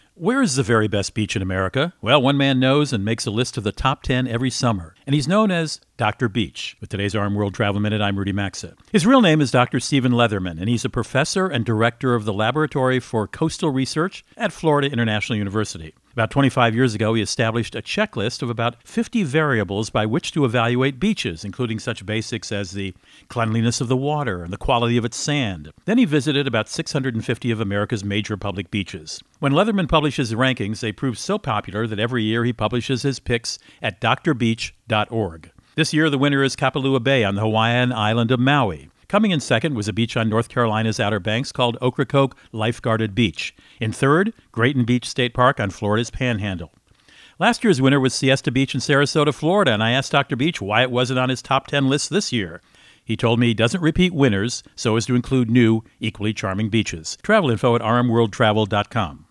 America's #1 Travel Radio Show
Host Rudy Maxa | Where is the Very Best Beach in America?